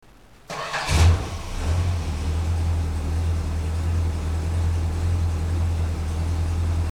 starting a 383 engine
440_start.mp3